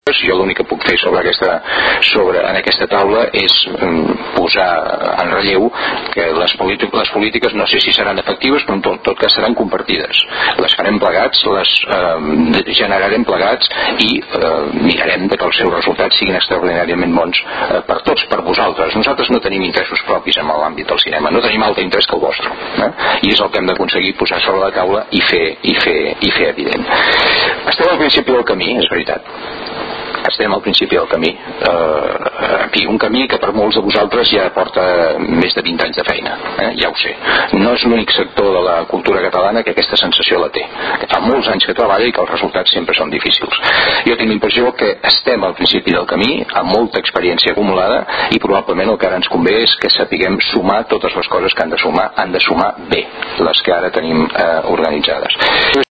Intervencions durant la roda de premsa
Tall de veu del conseller Mascarell